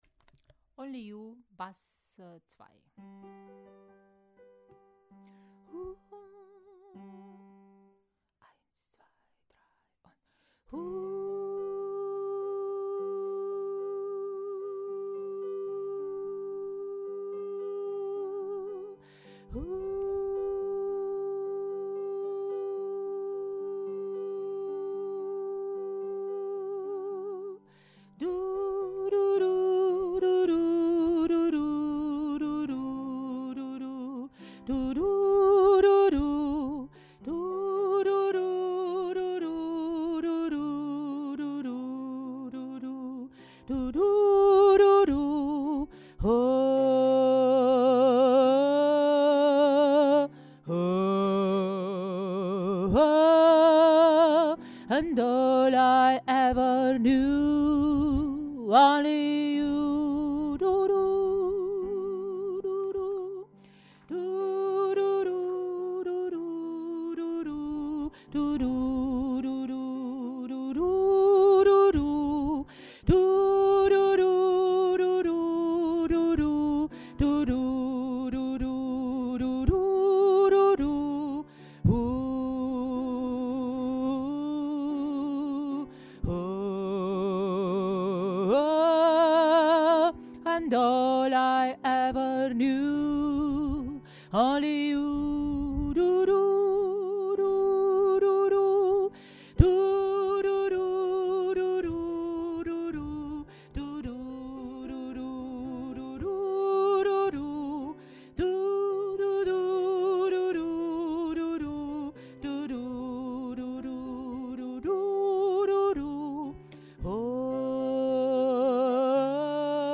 Only You – Bass 2